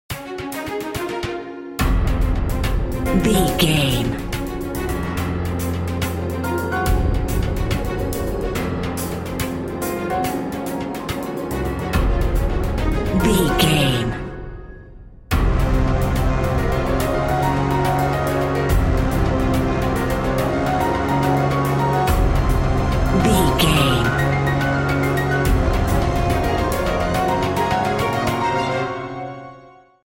Epic / Action
Fast paced
Phrygian
chaotic
intense
tension
brass
horns
percussion
piano
strings